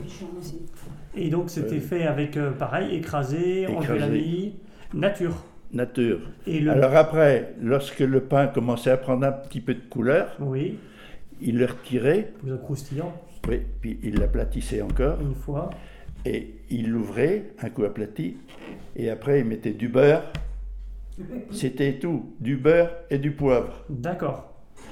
Enquête autour du préfou
Catégorie Témoignage